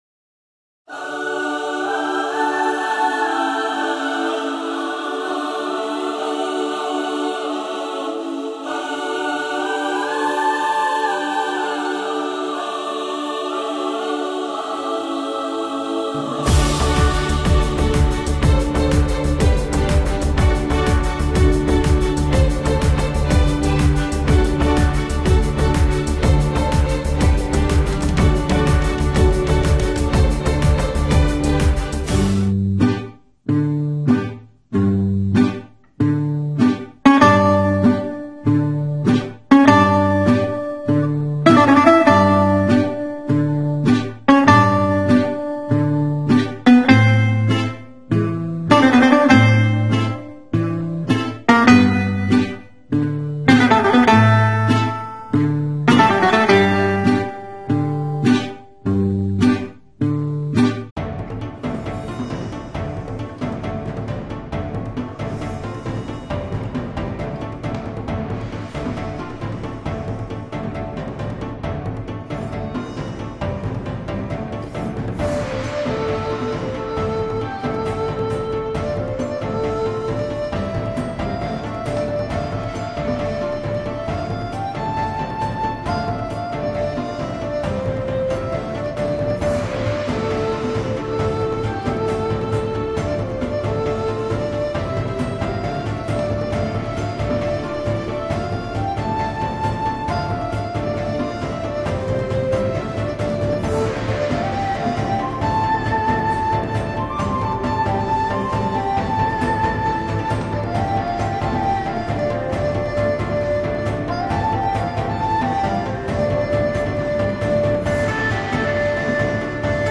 HUDBA k zonglerske choreografii ERASMUS
ZONGL_GALA_VECER_FSpS.wav